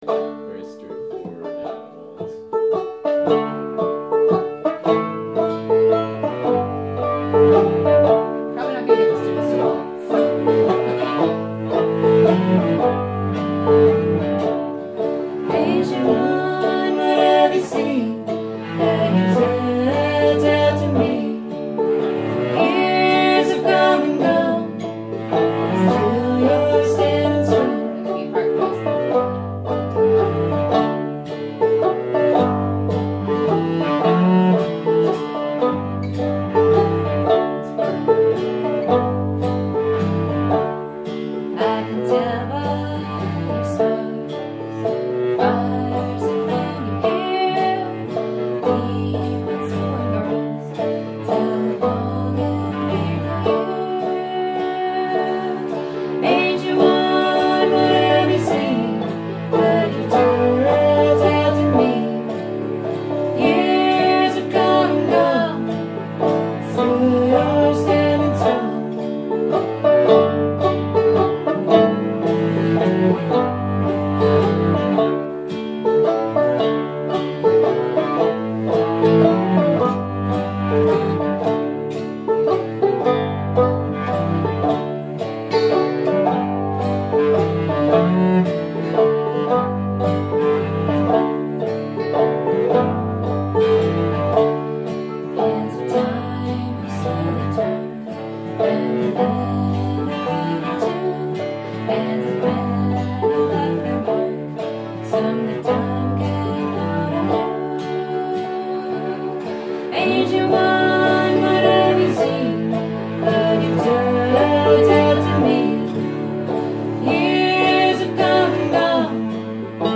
banjo, vocals
cello, guitar
They are classically trained, but they play old-time music and write a lot of their own material.
These recordings were made an hour earlier, during a quick practice session in my living room.